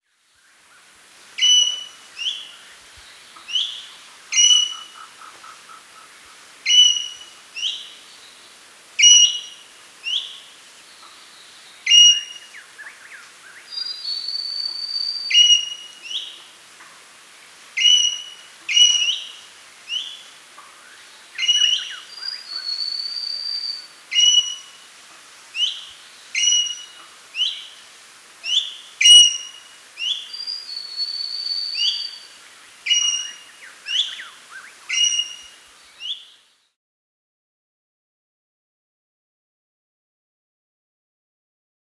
magpie
Common-Green-Magpie-Calls-QuickSounds.com_.mp3